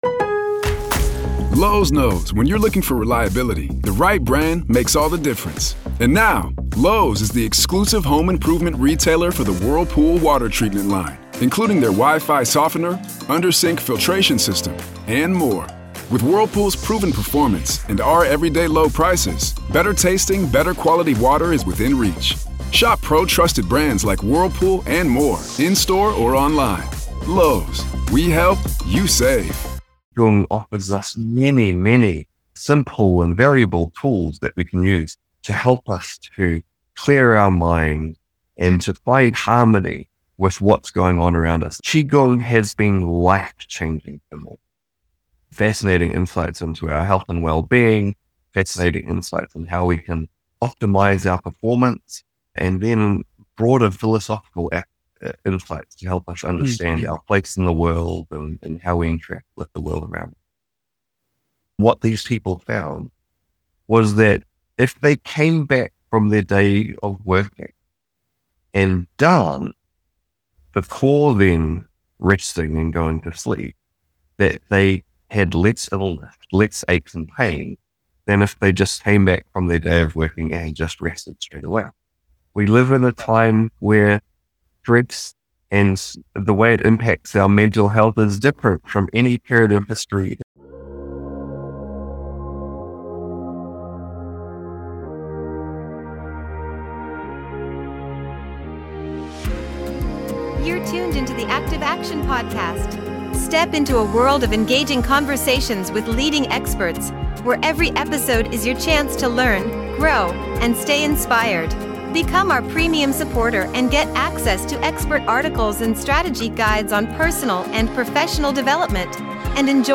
Dive into enlightening conversations on self development, health & wellness, lifestyle, education, and entertainment content.